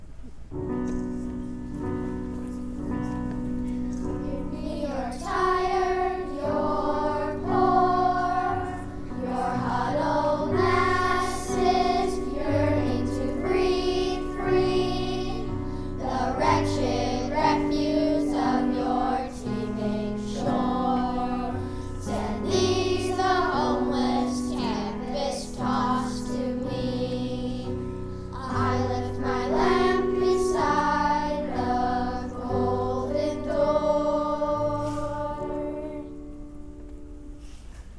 Then the children sing a hymn, based on the famous poem “The New Colossus” by Emma Lazarus, which is engraved on a plaque mounted on the Statue of Liberty.
In our performance, the children sing the somber version first.